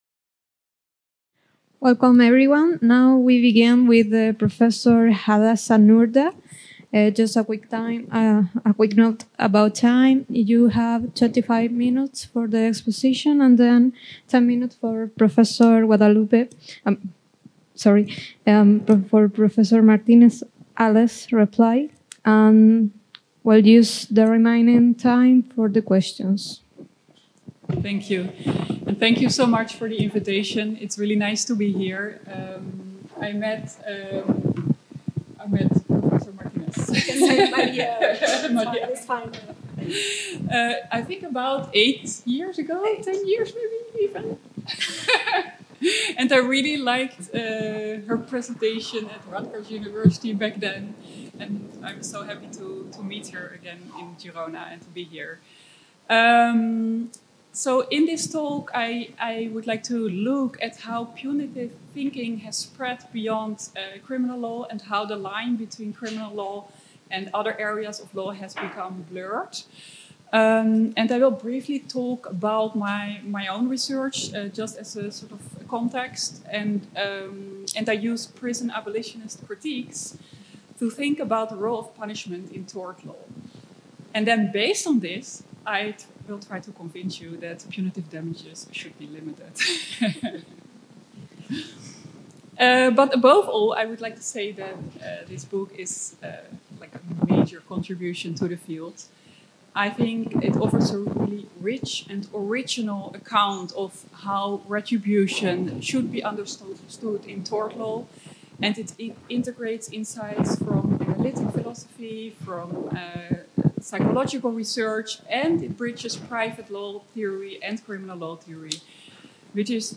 The 9th Workshop on Philosophy of Private Law, entitled "Torts & Retribution," organized by the Chair of Legal Culture at the University of Girona, was held on February 24, 2026.